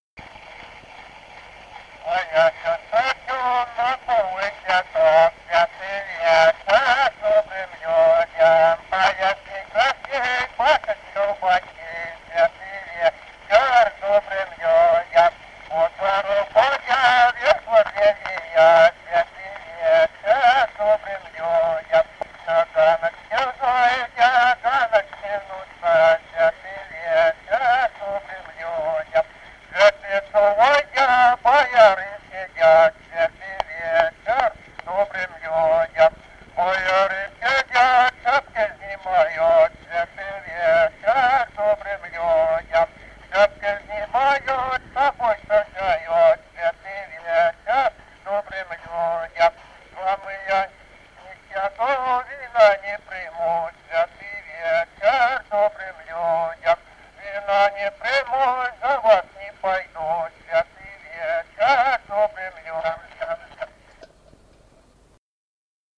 Народные песни Стародубского района «А ясён, красён», новогодняя щедровная.
1951 г., д. Камень.